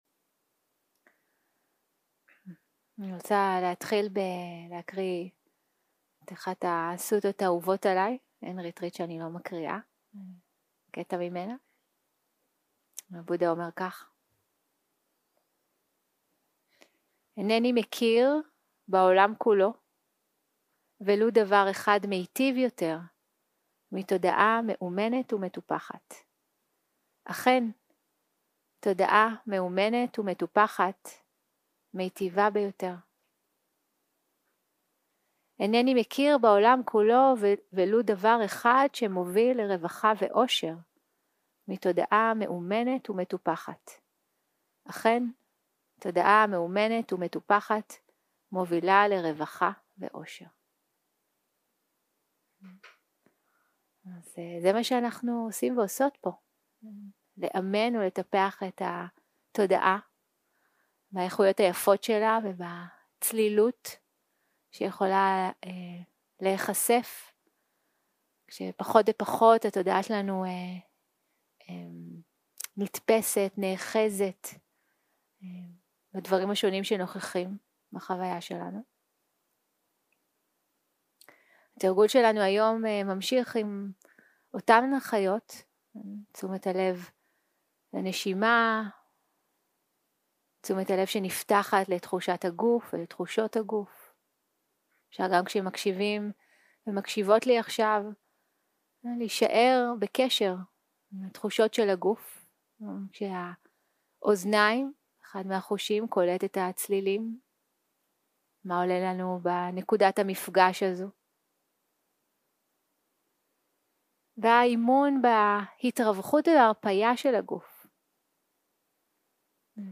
יום 3 - הקלטה 6 - בוקר - הנחיות למדיטציה - מבט רחב בעבודה עם אורחי התודעה 0:00 0:00 סוג ההקלטה: סוג ההקלטה: שיחת הנחיות למדיטציה שפת ההקלטה: שפת ההקלטה: עברית